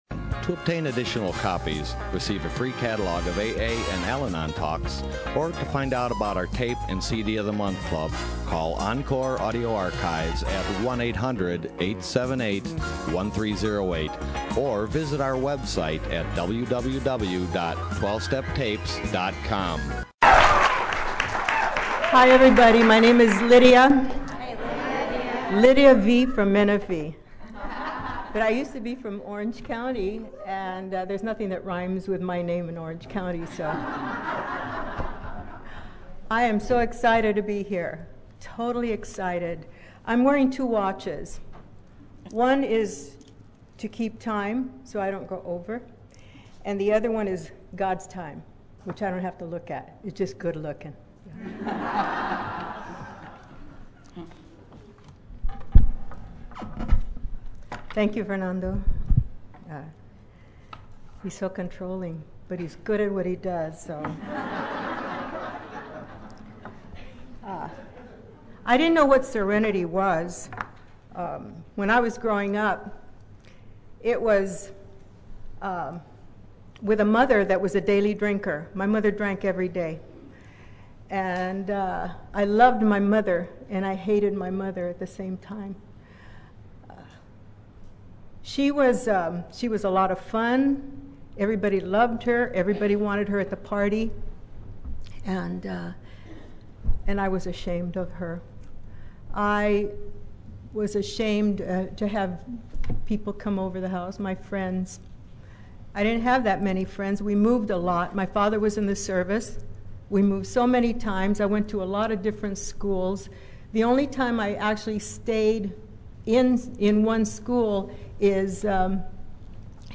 Orange County AA Convention 2014